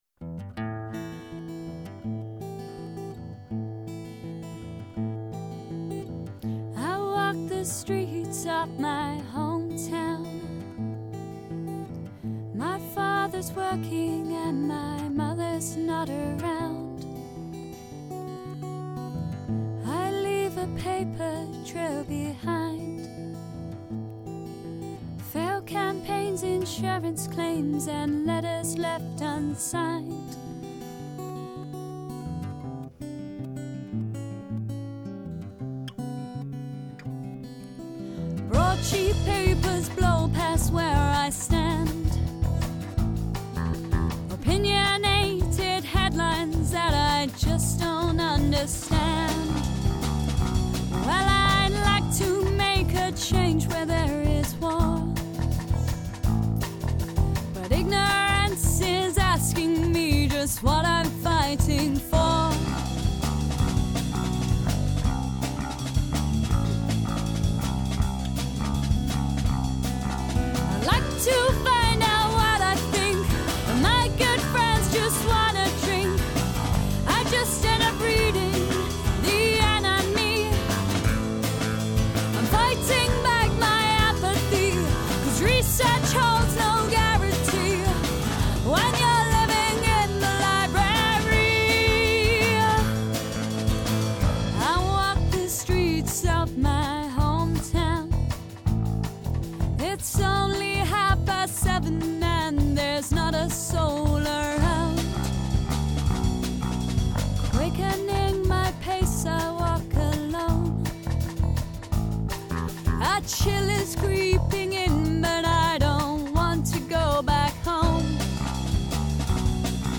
"Road Runs Dry" Drum n Bass Folk!!
We recorded 4 tracks as a demo, but haven't really done much with it since - thus the songs have remained pretty much unheard outside our band.
We nearly dropped it for good - until I had an idea to up the tempo and put a breakbeat style drums and bass.
Music/lyrics/vocals/violin Me: Guitar
Drums Cheers!